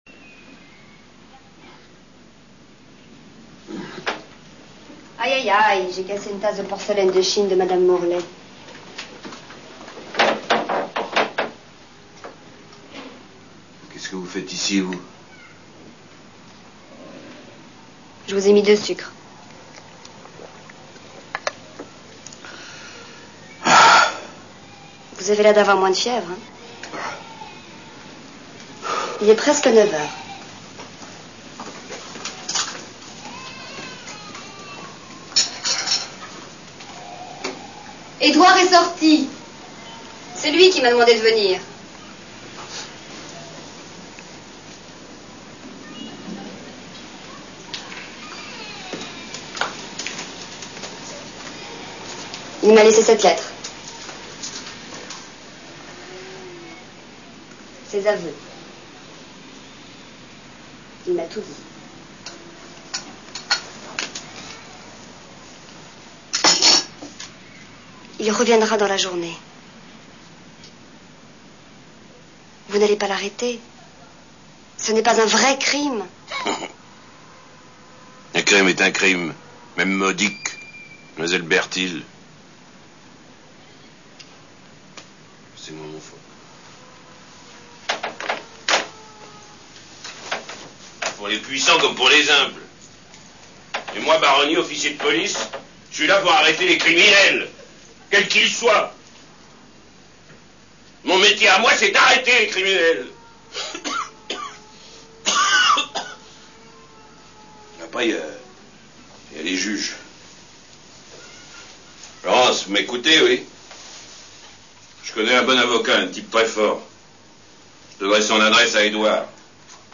Nous vous offrons en complète EXCLUSIVITE, les dialogues du films en MP3 où Dorothée est présente.
Dorothée et Philippe Noiret (3)       : 2 mn 13 (524kb)